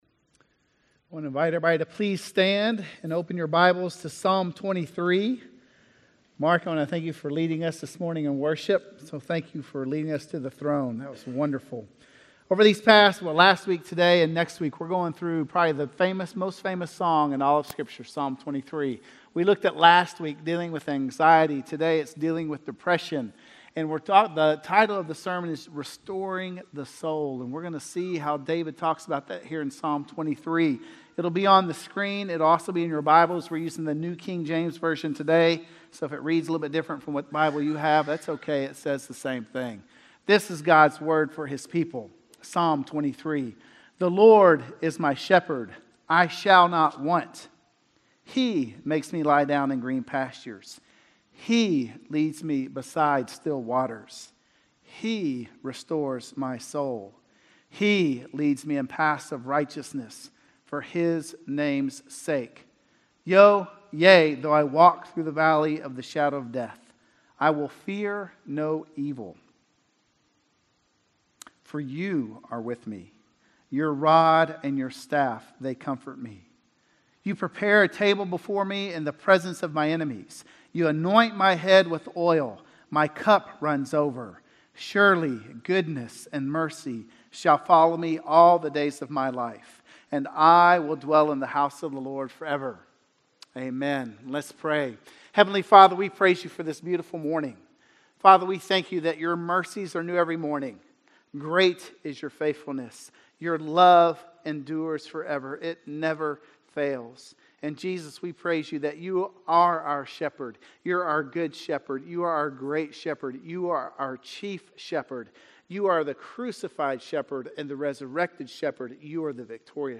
Dealing With Depression - Sermon - Woodbine